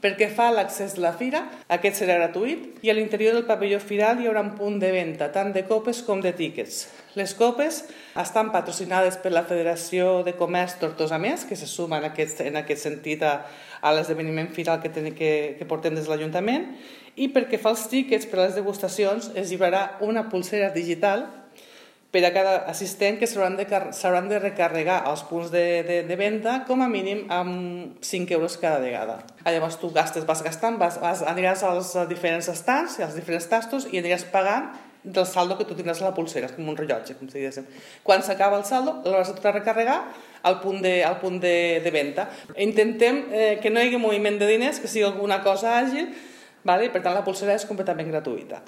Una de les novetats que incorpora el Festast és la pulsera digital a través de la qual els visitants podran accedir als tastos que oferiran els expositors. Sònia Rupérez, regidora de fires de Tortosa…